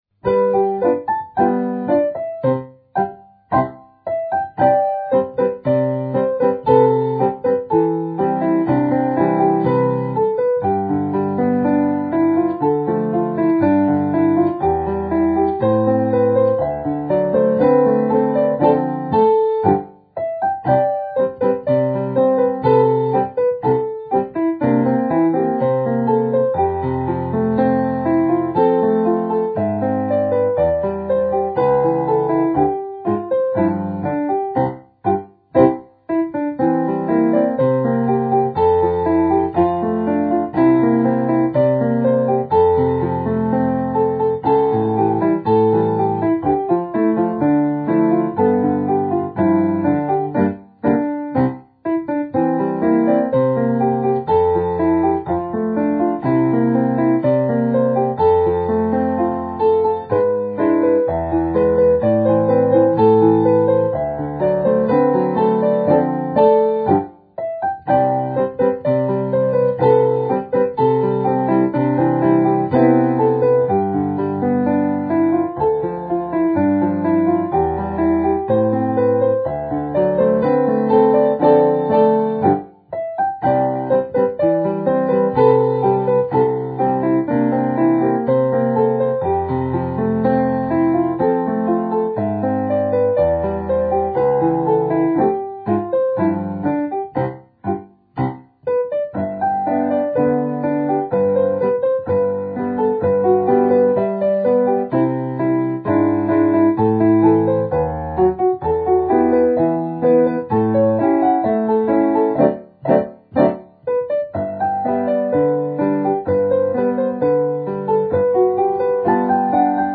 A Tune
piano